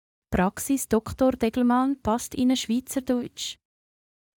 As soon as I add a second agent within my squads workflow, the language gets distorted.
This is the normal audio with just one assistant in the squad